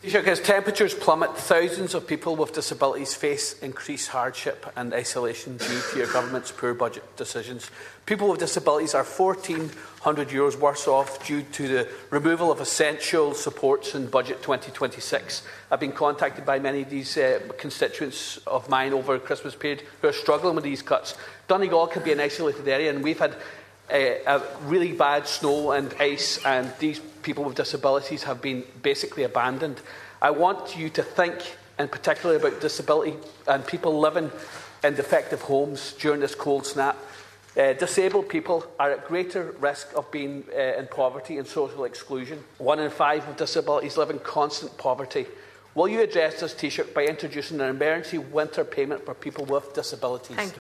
That’s according to Donegal Deputy Charles Ward, who was speaking in the Dail this afternoon.